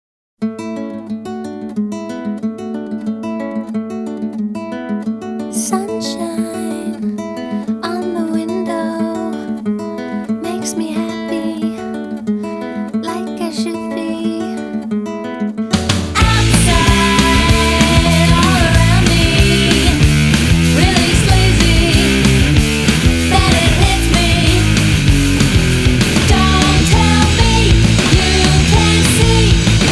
1996-10-01 Жанр: Рок Длительность